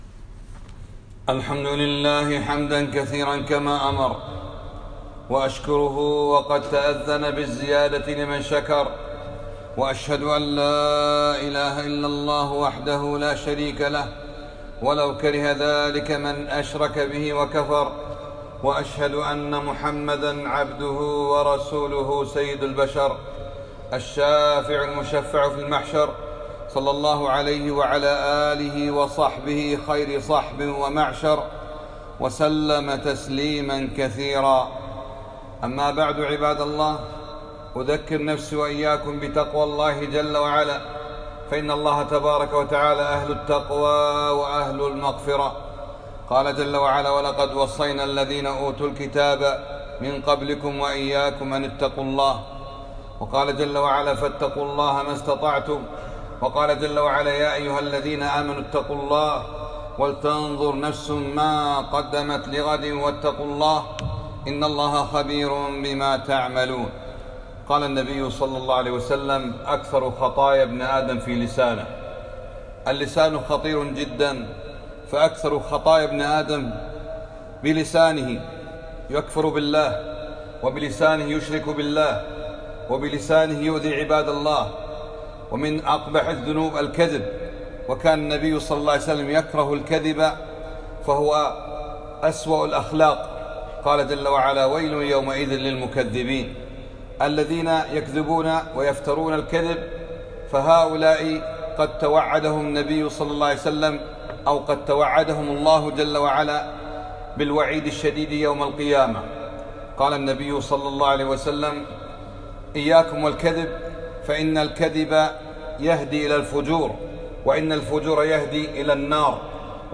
خطبة - خطورة اللسان